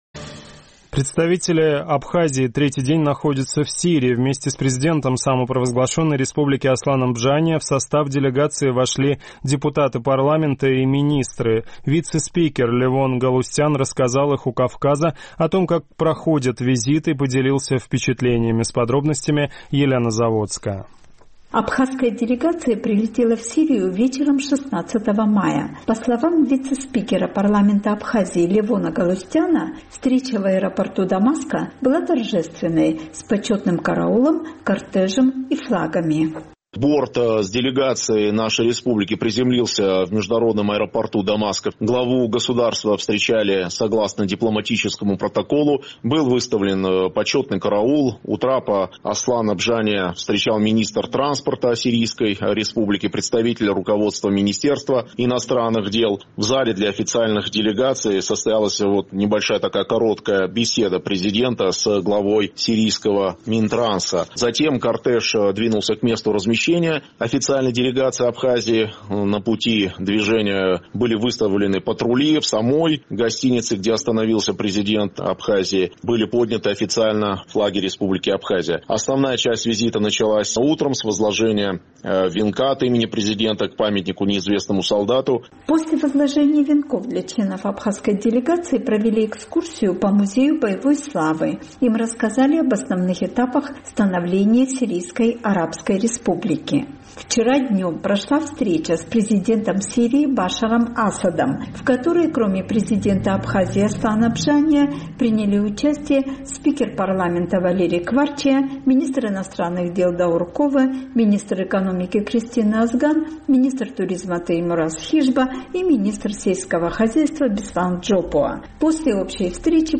Представители Абхазии третий день находятся в Сирии, вместе с президентом Асланом Бжания в состав делегации вошли депутаты парламента и министры. Вице-спикер парламента Левон Галустян рассказал «Эху Кавказа» о том, как проходит визит, и поделился впечатлениями.